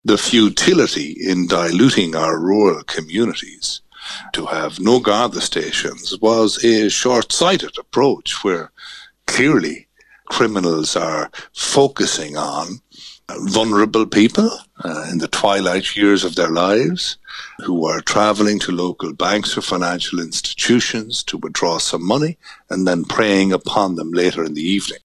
Deputy Marc McSharry is slamming the decision to close rural garda stations which he says has led to a spike in burglaries: